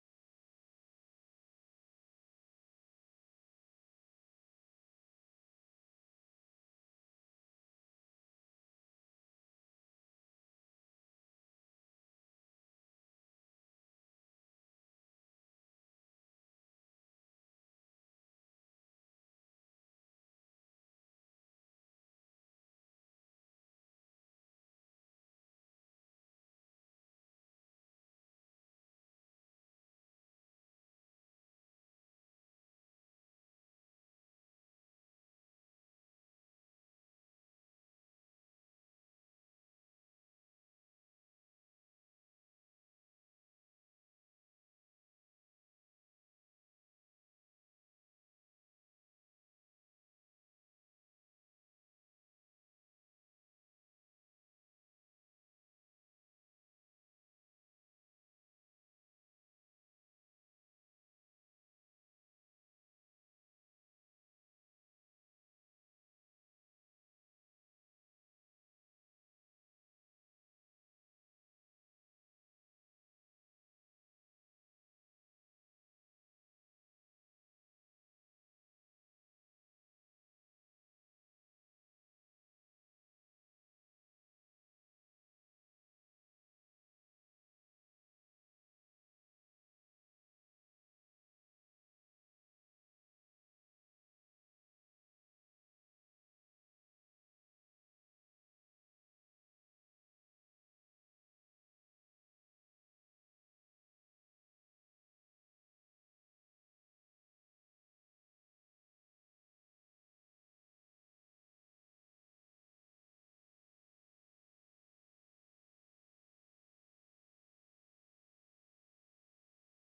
Vägra följa John : hemligheten bakom ett lyckligt liv / Ljudbok